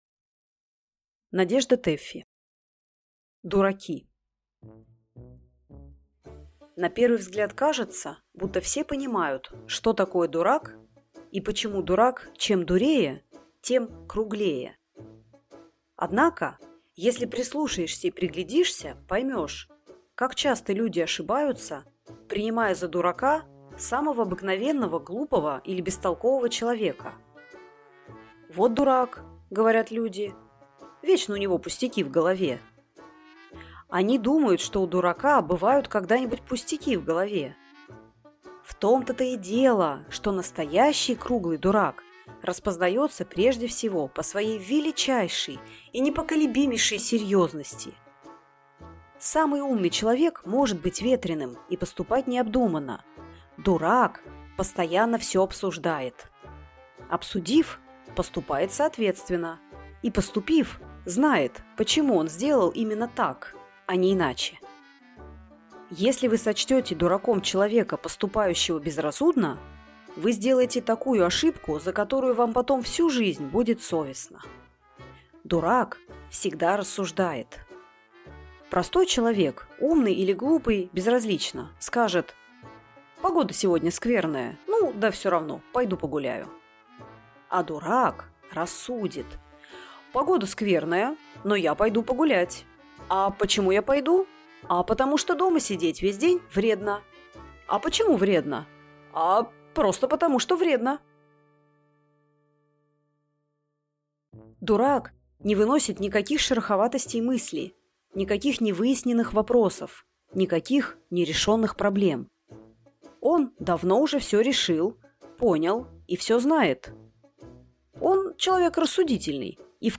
Аудиокнига Дураки | Библиотека аудиокниг